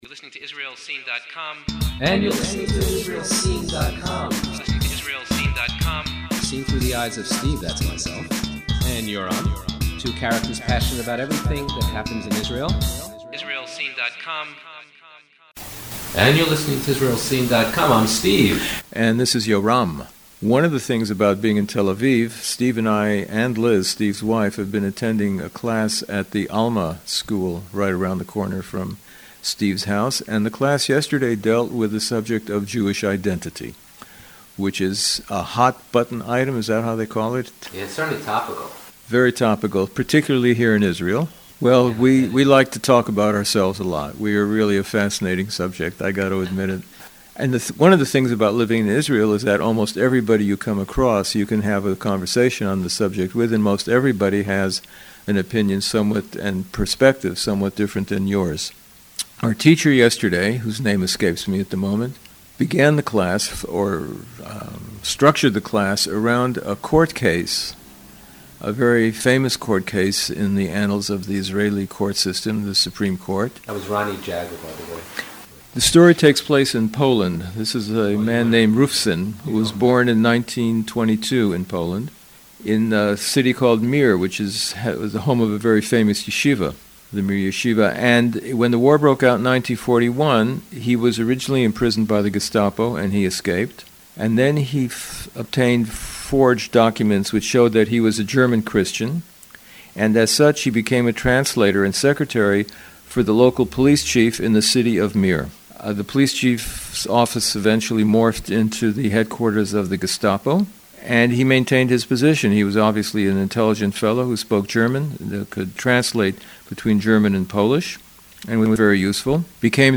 What does it mean to be a Jew? What is self-identity and is it enough to be identified as a Jew legally? Just a note: Because of some technical glitch the quality of the broadcast was not up to our normal standards.